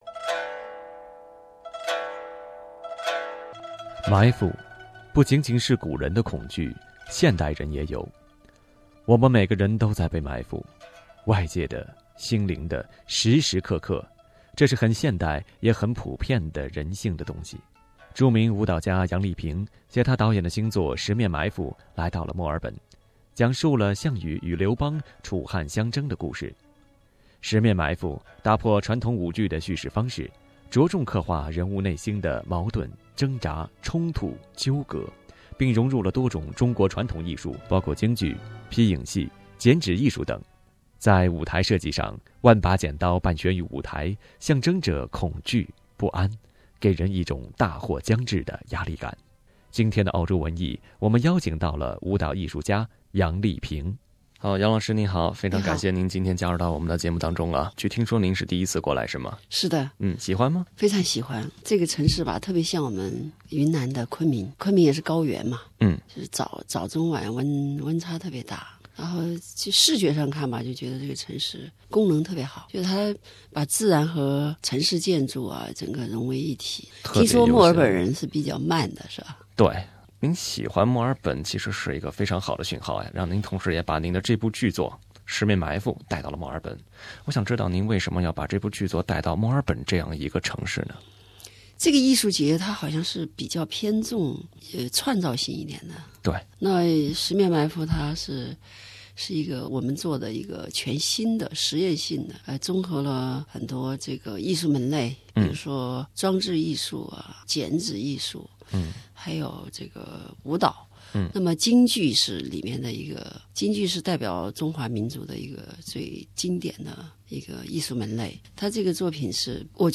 2017年的墨尔本国际艺术节中一个重要演出，是中国舞蹈艺术家杨丽萍担任总编剧的大型舞剧《十面埋伏》。杨丽萍做客SBS普通话“澳洲文艺”节目，介绍《十面埋伏》的创作初衷和她的“澳洲映像”。